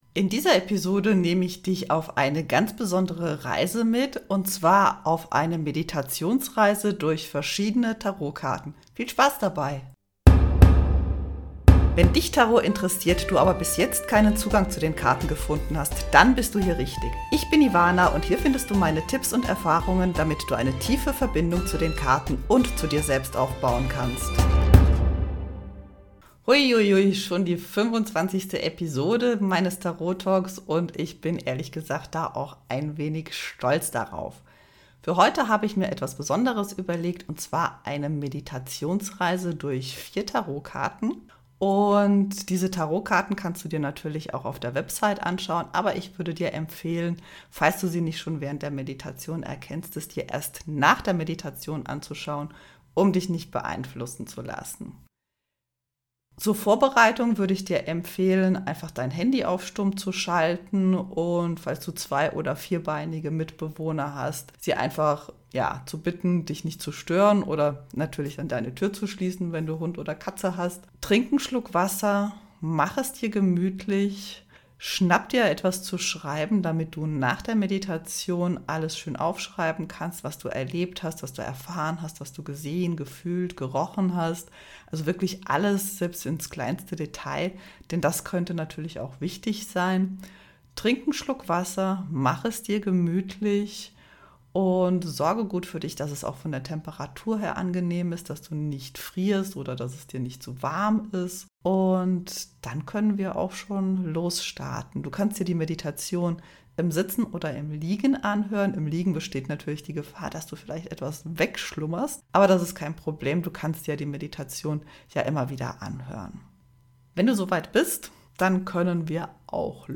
Ich lade dich heute zu einer besonderen Meditation ein. Du reist durch vier Tarot-Karten, reflektierst über bestimmte Lektionen aus der Vergangenheit, triffst eine Entscheidung und findest einen Kraftgegenstand, der dich zumindest eine Weile auf deinem Weg begleiten wird.